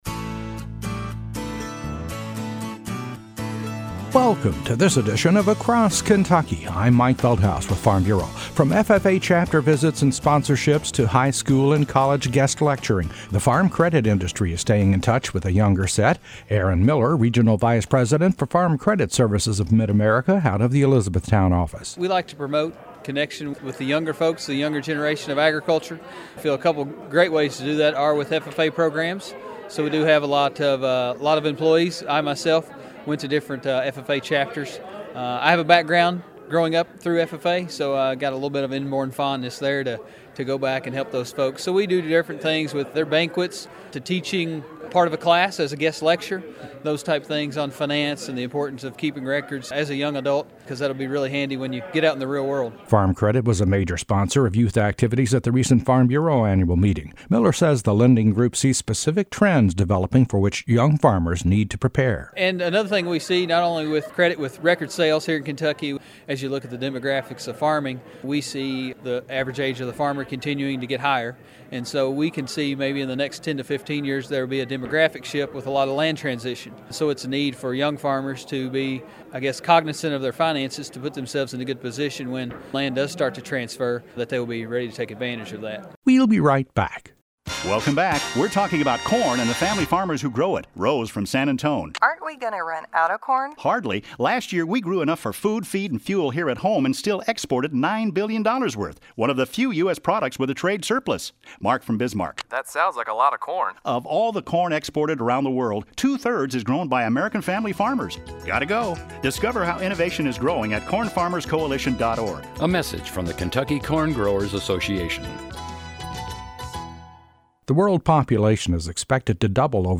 A feature report